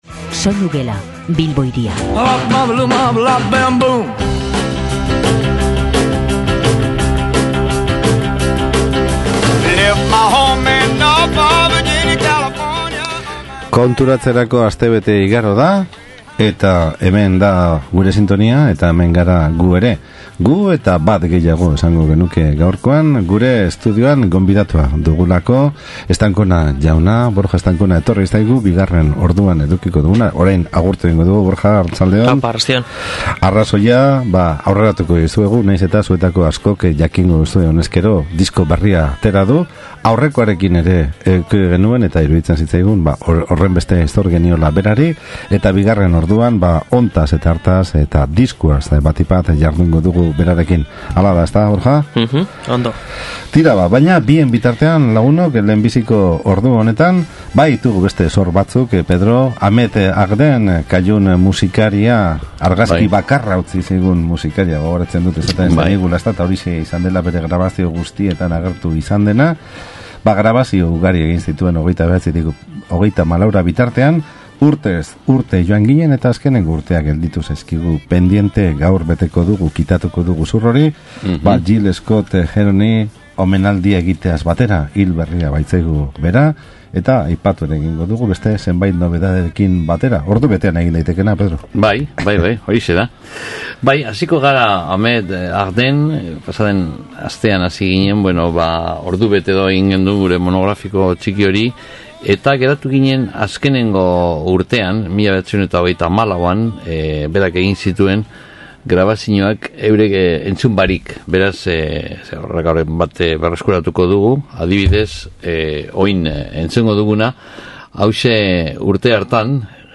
Eta galdera eta erantzunen artean, diskoa apurka-apurka aletzeko aukera izan dugu.